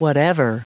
Prononciation du mot whatever en anglais (fichier audio)